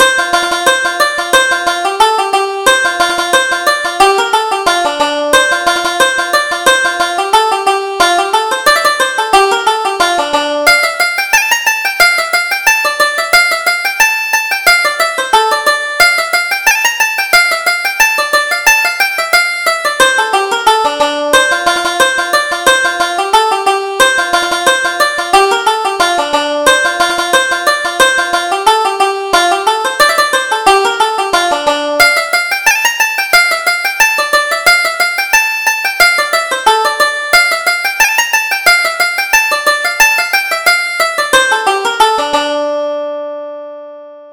Reel: The Dublin Lasses